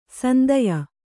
♪ sandaya